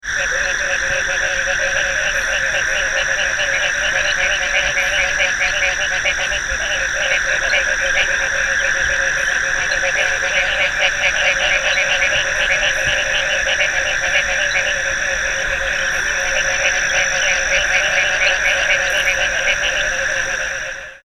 Advertisement Calls
The advertisement call of the Mexican Treefrog is a series of short quick notes medium in pitch with occasional chuckles. The notes are repeated 5 to 12 times in 2 or 3 seconds at 2 to 3 second intervals.
Below are recordings of the advertisement calls of a group of Mexican Treefrogs recorded at night from the edge of a resaca in Cameron County, Texas (shown below as it looked in daylight.) The high-pitched drone of insects can be heard in the background.